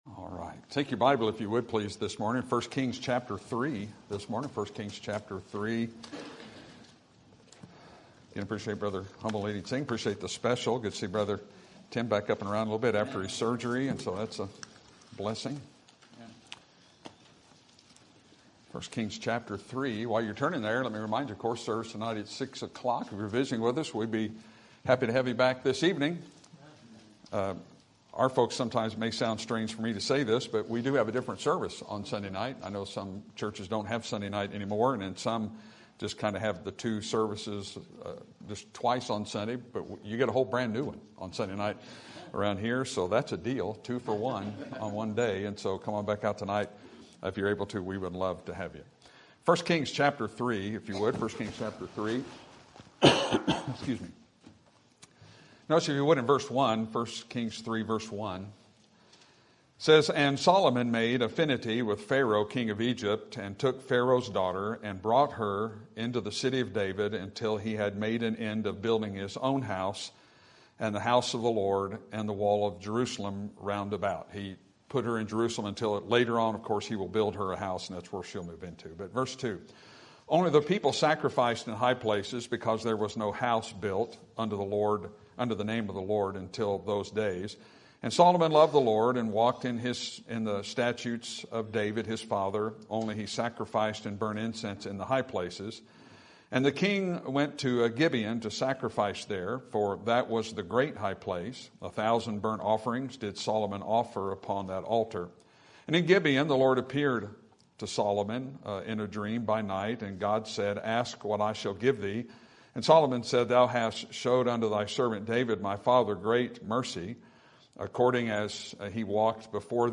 Sermon Topic: Men Who Encountered God Sermon Type: Series Sermon Audio: Sermon download: Download (23.22 MB) Sermon Tags: 1 Kings Wisest Flawed Solomon